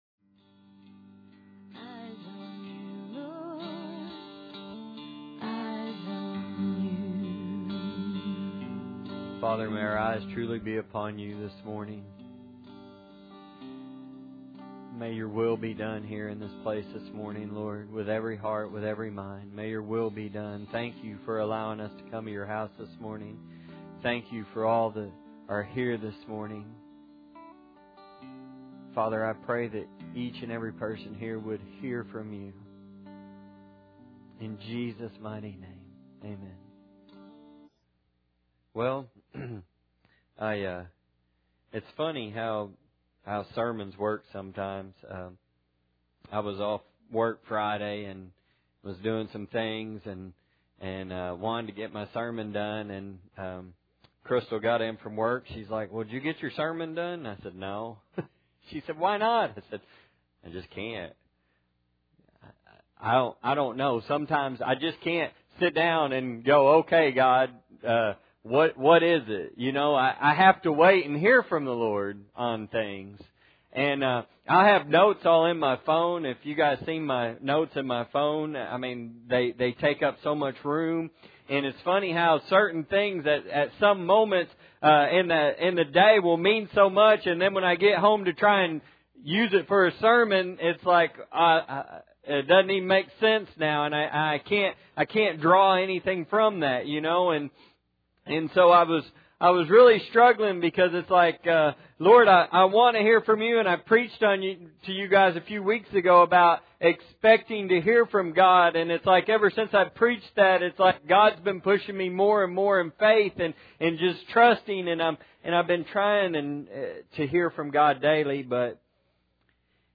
Matthew 13:24- Service Type: Sunday Morning Bible Text